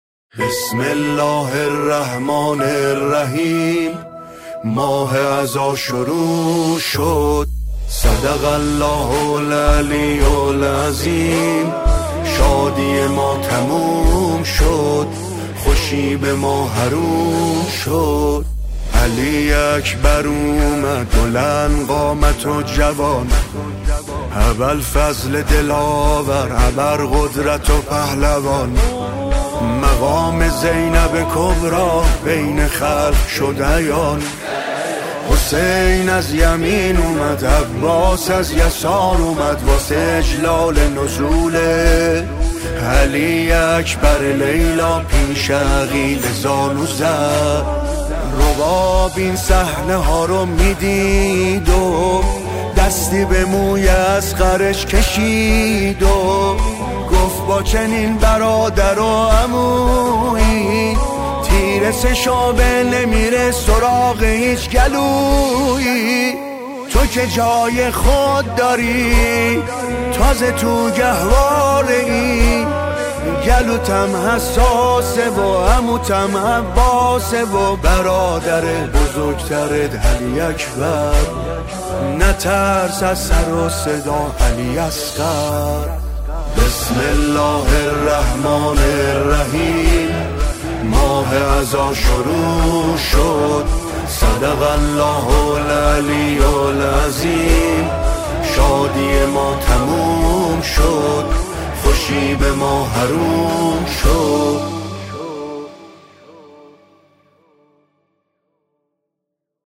ویژه شب اول محرم